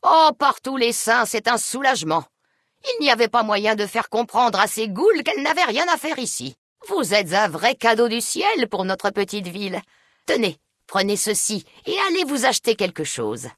Dialogue audio de Fallout: New Vegas